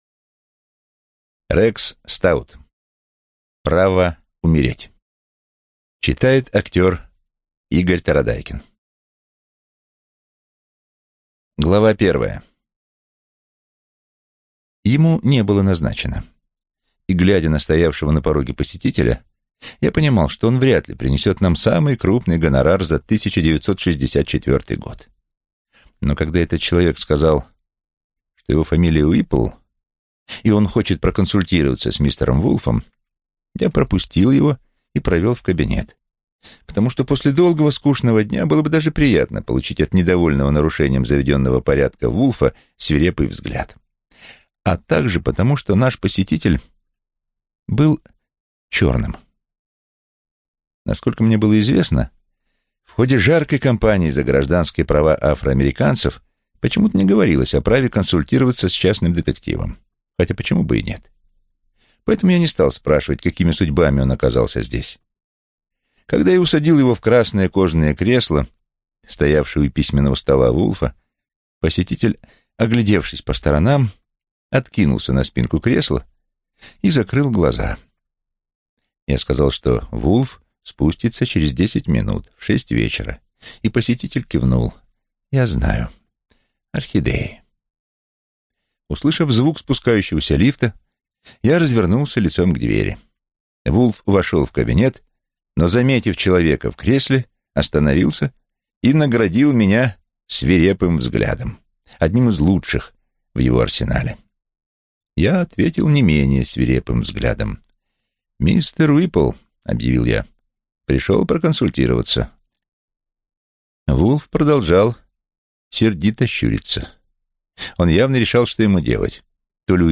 Аудиокнига Право умереть | Библиотека аудиокниг